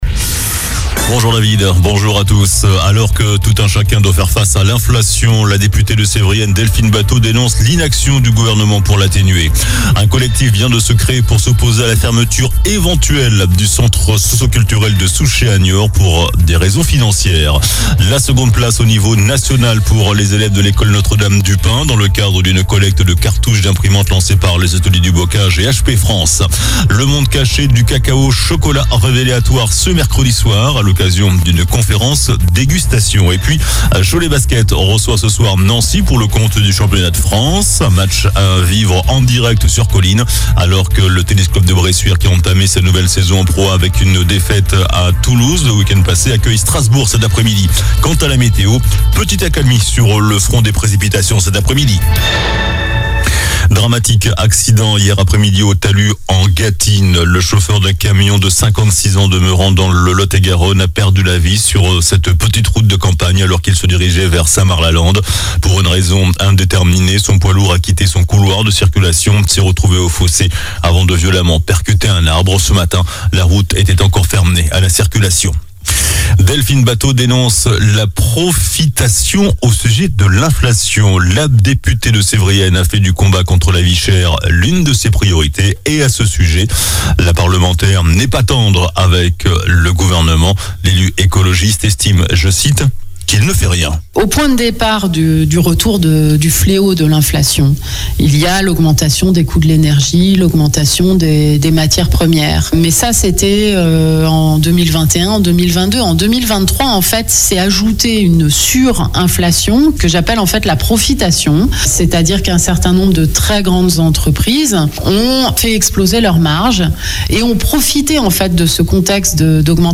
JOURNAL DU MERCREDI 15 NOVEMBRE ( MIDI )